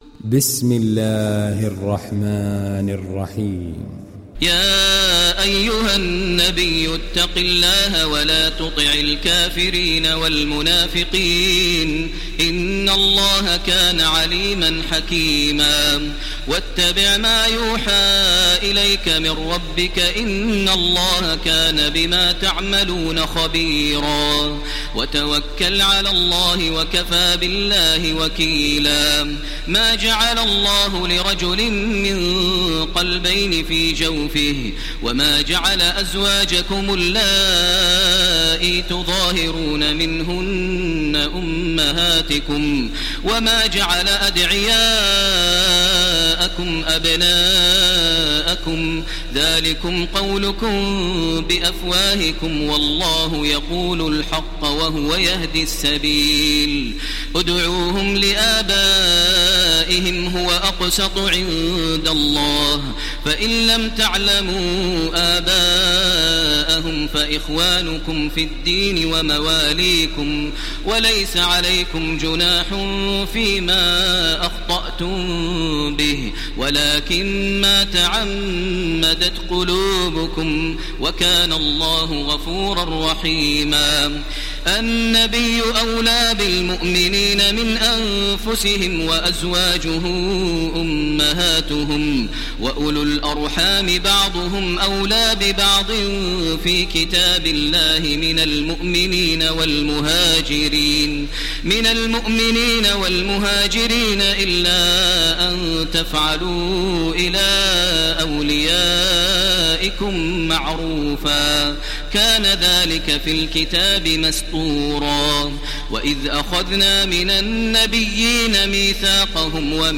ডাউনলোড সূরা আল-আহযাব Taraweeh Makkah 1430